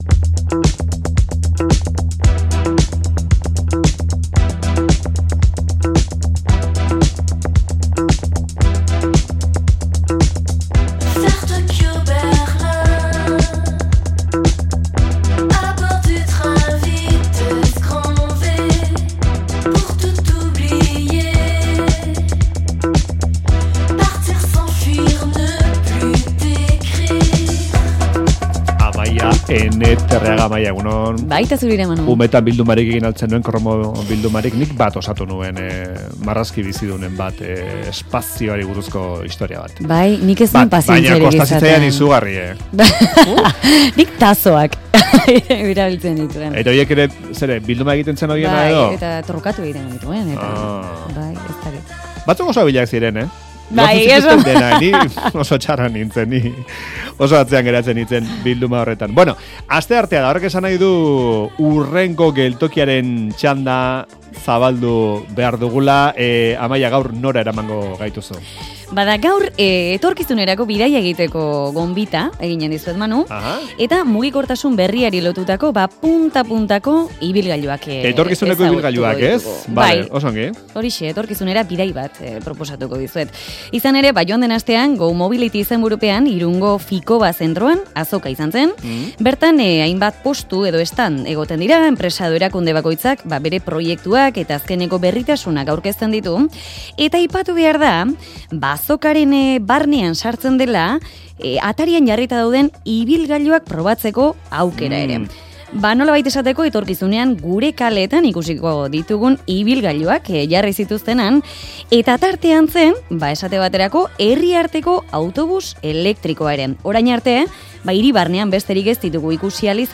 Audioa: Irungo Ficoba eremuan mugikortasun berriari lotutako azken berrikuntzak ikusi ditugu, GO MOBILITY izeneko azoka industrialean. Etorkizunerako bidaia egin dugu eta azokako langile, arduradun, ikasle eta bisitariekin elkartu gara.